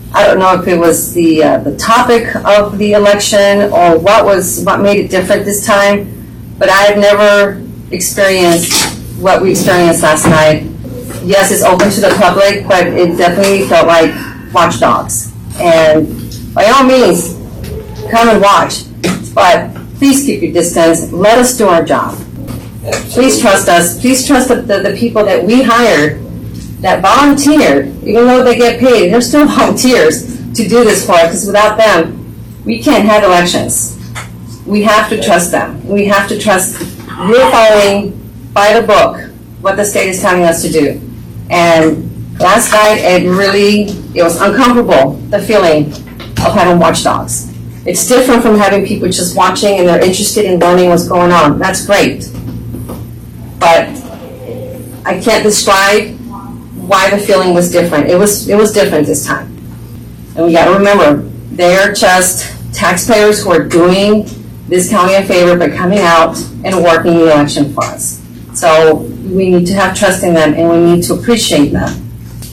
Walworth County Auditor addresses “watch dogs” during ballot counting
The Walworth County Commissioners met Wednesday morning (September 29th) to canvass the votes from the opt-out election.  County Auditor Eva Cagnones informed the commissioners that election workers had been distracted by members of the public while counting ballots Tuesday night.